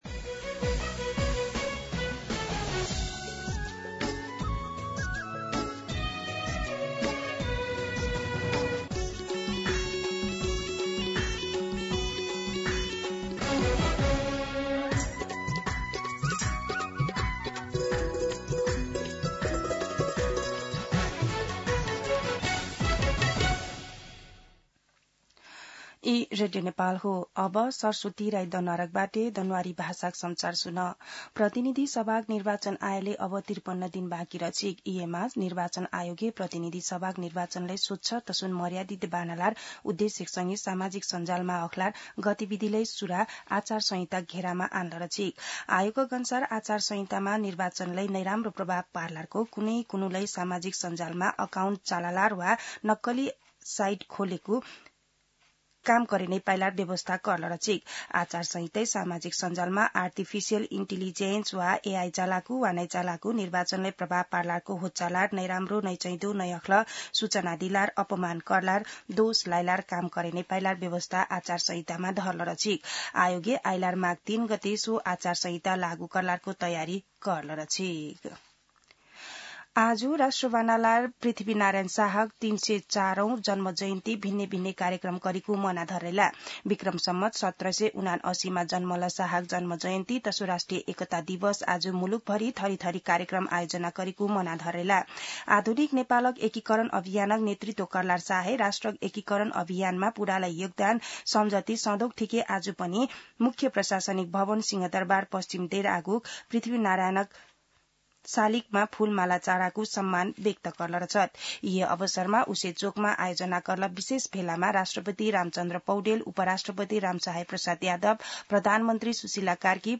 दनुवार भाषामा समाचार : २७ पुष , २०८२
Danuwar-News-1.mp3